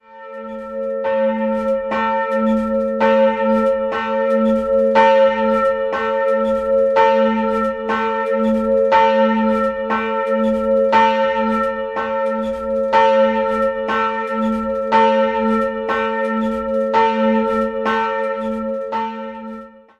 St. Martin am Ybbsfelde, "Friedensglocke" in der Pfarrkirche St. Martin
"Friedensglocke" a'
St. Martin am Ybbsfelde Friedensglocke.mp3